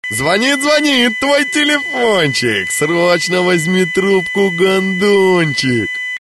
Прикольные фразы
• Пример реалтона содержит искажения (писк).